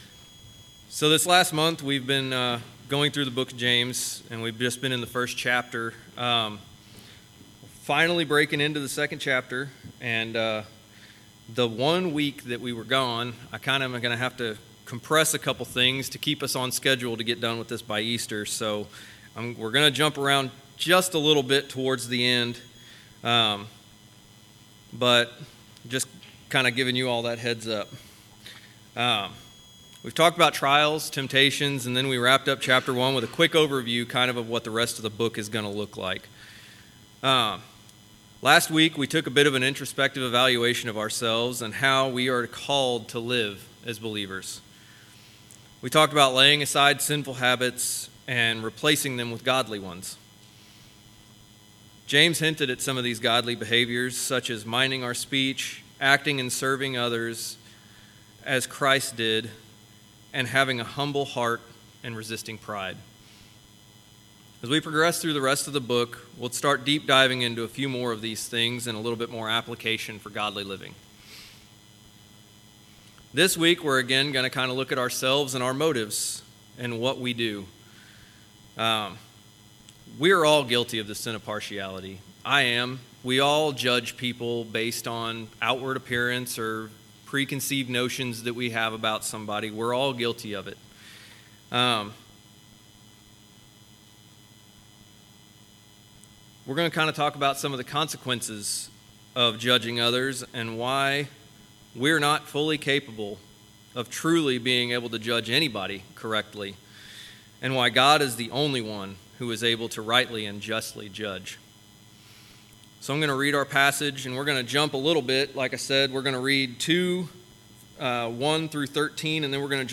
Walking the Walk Passage: James 2:1-13 Service Type: Sunday Worship Service « Responding to the Gospel